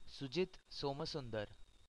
pronunciation (born 2 December 1972) is an Indian former cricketer who played domestic cricket for Karnataka and played two One Day Internationals for India in 1996.